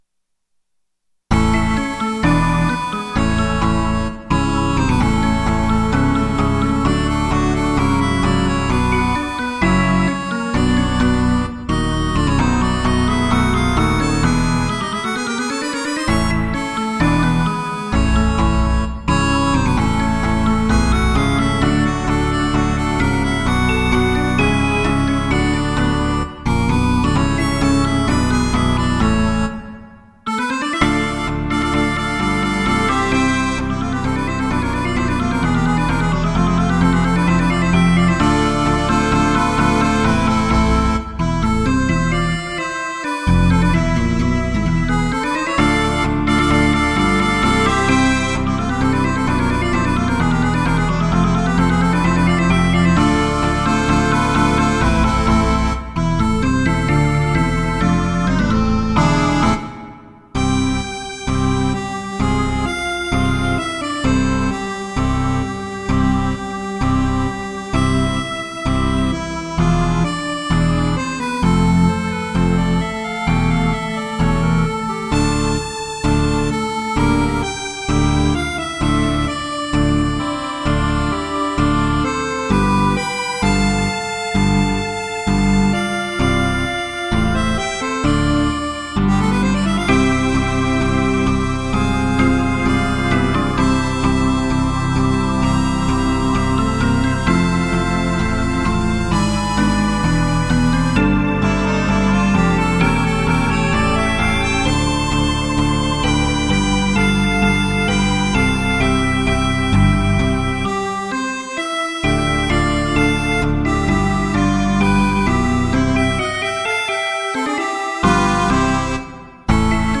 Танго (для двух аккордеонов